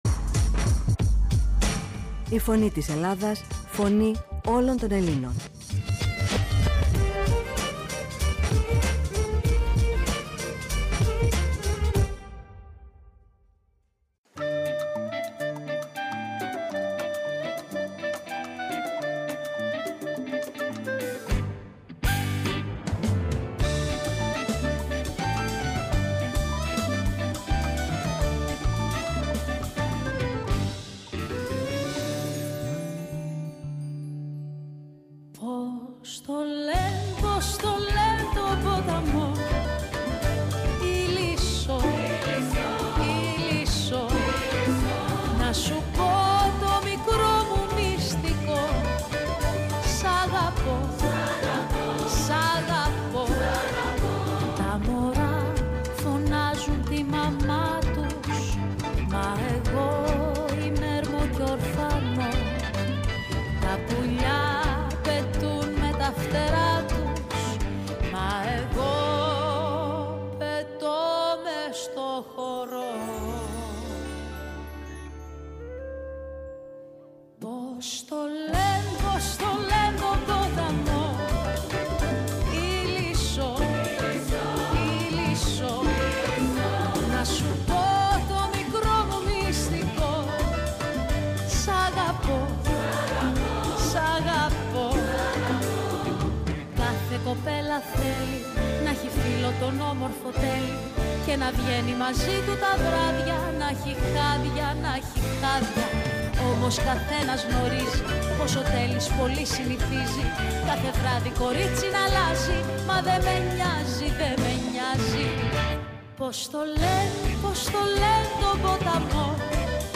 Στην Ινδονησία, τη μεγάλη χώρα της ΝΑ Ασίας με τα 18,000 νησιά, ήταν αφιερωμένη η εκπομπή «Η Παγκόσμια Φωνή μας» στο ραδιόφωνο της Φωνής της Ελλάδας.